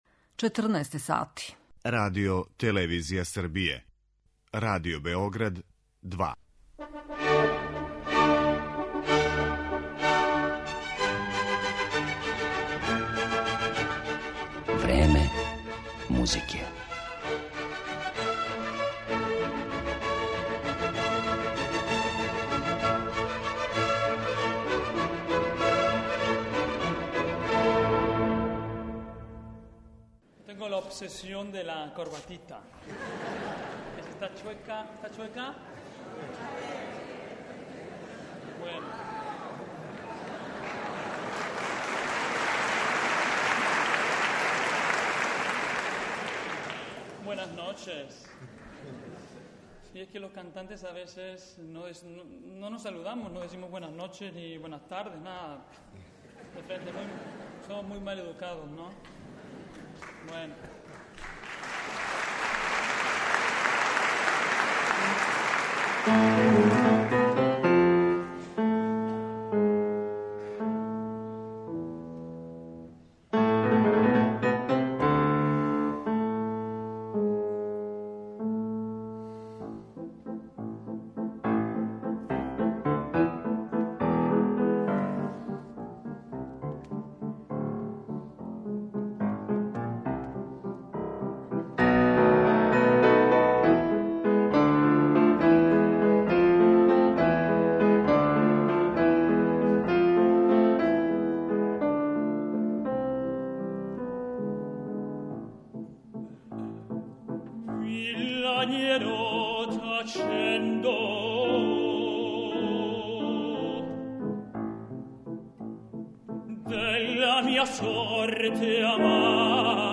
Данашњу емисију Време музике посвећујемо перуанском тенору који је међу најтраженијима на престижним оперским сценама.